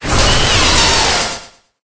Cri_0884_EB.ogg